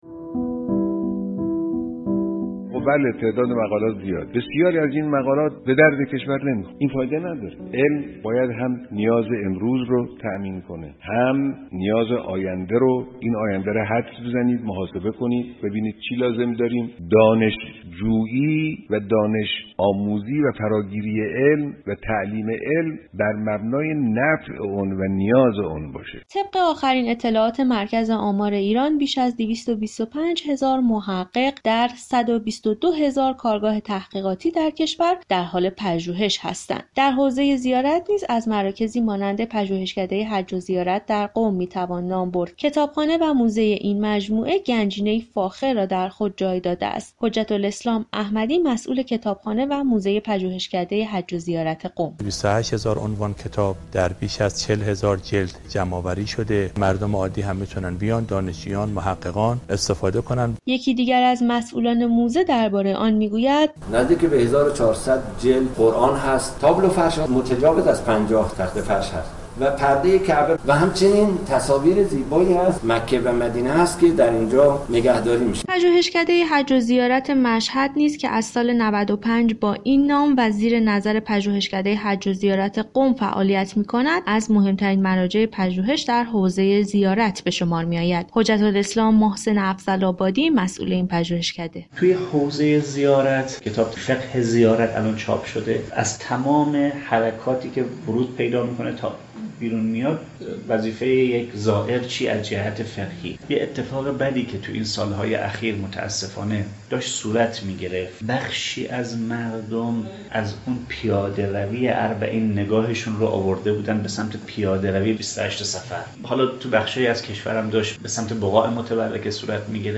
خبر نگار رادیو زیارت در رابطه با ضرورت پژوهش های خلاقانه و مناسب توسط اندیشمندان و محققان اسلام‌شناس ، با نیاز زائران در زمینه اندیشه اسلامی، دین و مفهوم زیارت،گزارشی آماده كرده است.